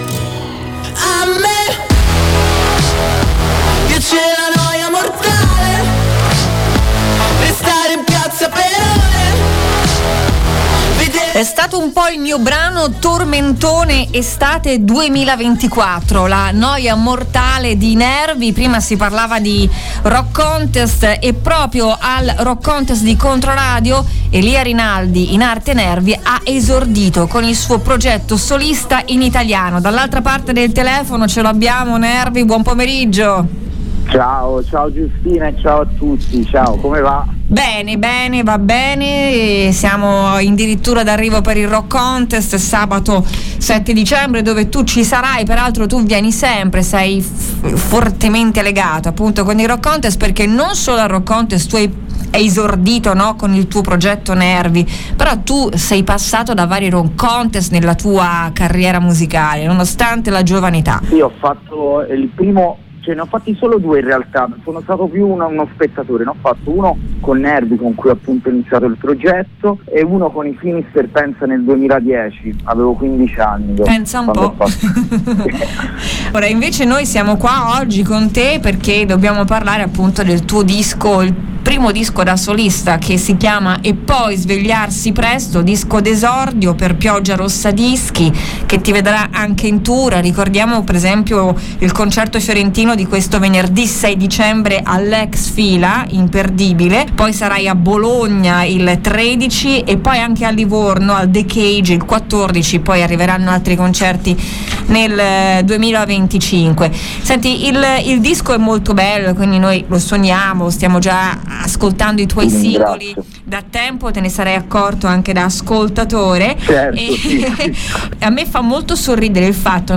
L'intervista 🎧